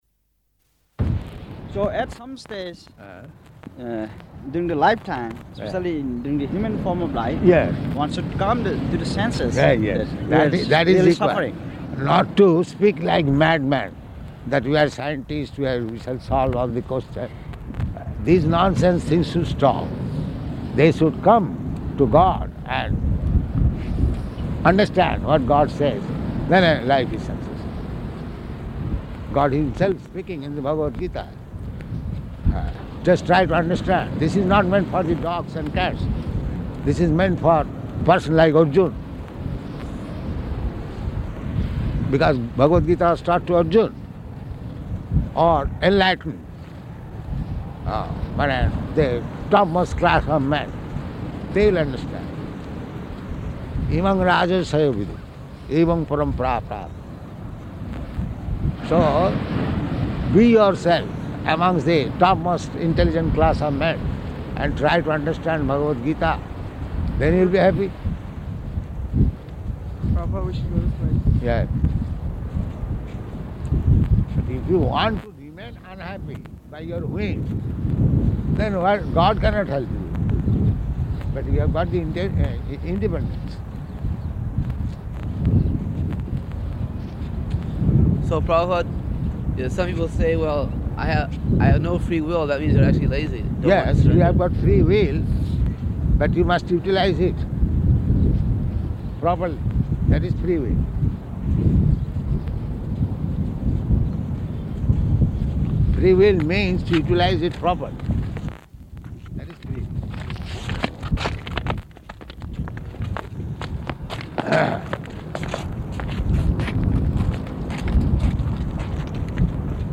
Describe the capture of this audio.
-- Type: Walk Dated: December 4th 1973 Location: Los Angeles Audio file